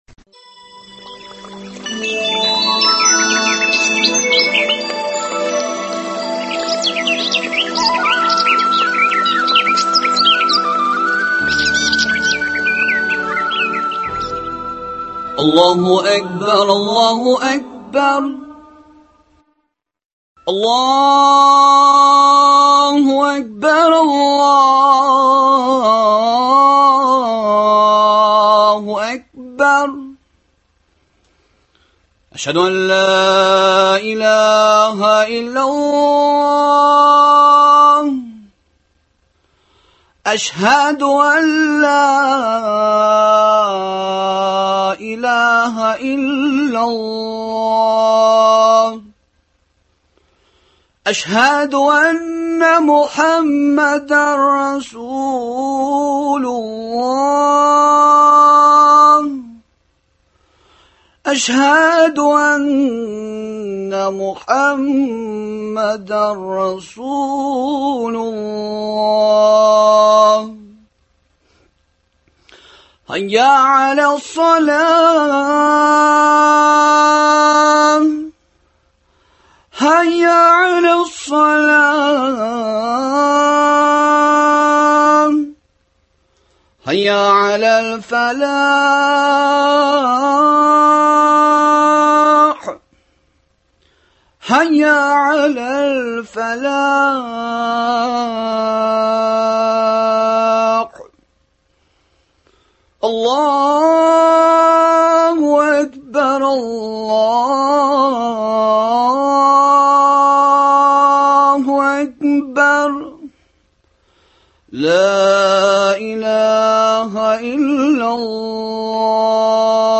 намаз, бу хакта төрле мәсхәбләрдә туа торган бәхәсле моментлар, безнең Әбү-Хәнифә хәзрәтләре мәсхәбенең үзенчәлекләре һәм нормалары һәм Идел буе татарларының намазларының нигезләре хакында әңгәмәләр циклы.